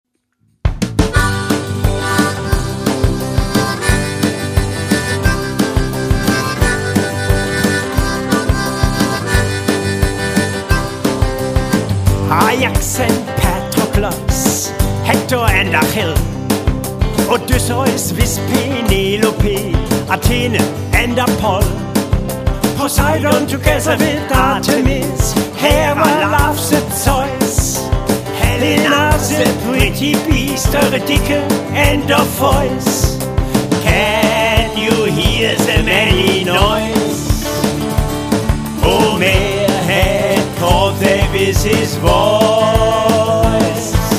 Authentische Lieder eines Abenteurers und Straßenmusikers.